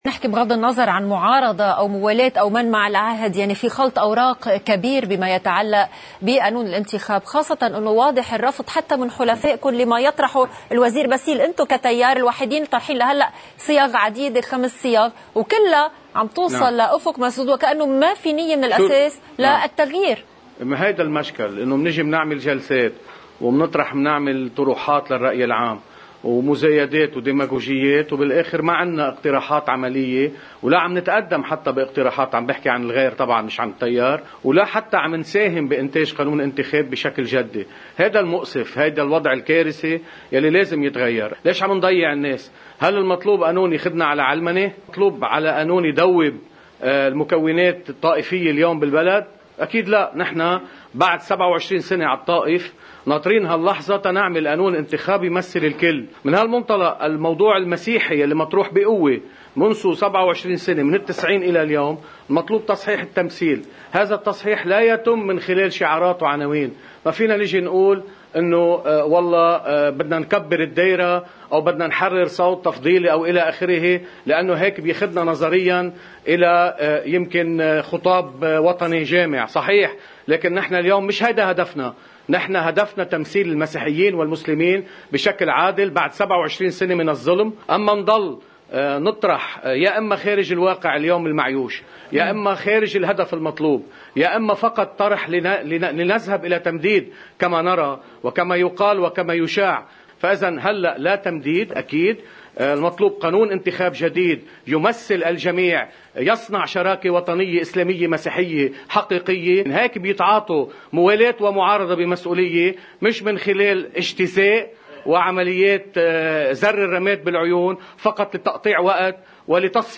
مقتطف من حديث النائب إبراهيم كنعان لقناة الـ”LBC”: